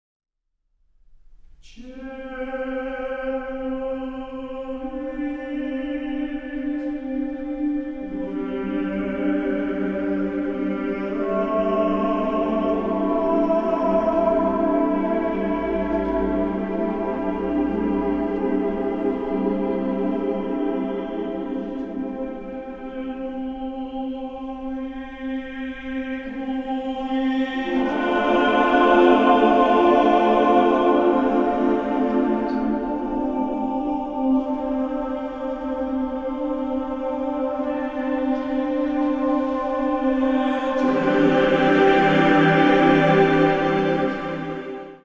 for cello and male choir